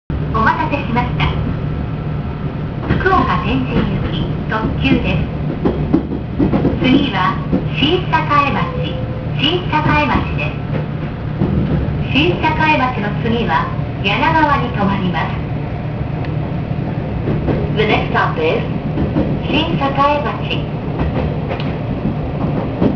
・8000形 車内自動放送